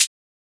Closed Hats
edm-hihat-35.wav